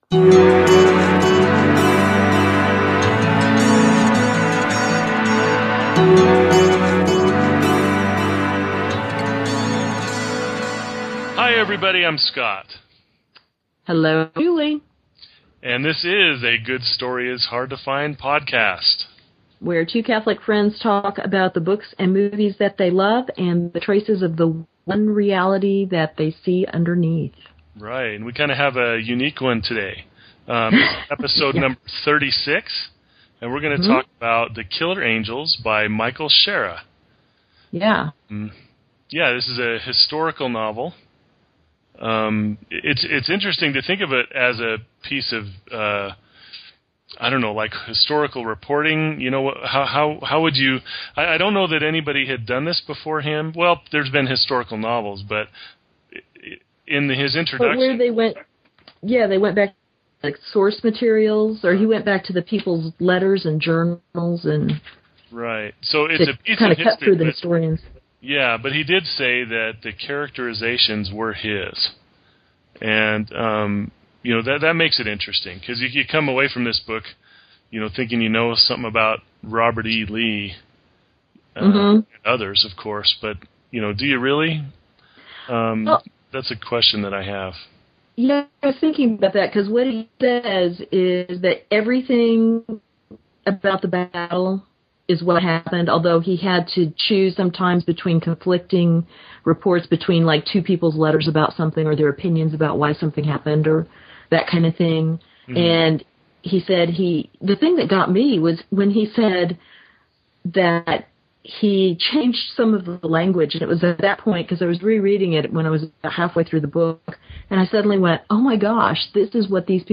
Two Catholics talking about books, movies and traces of "the One Reality" they find below the surface.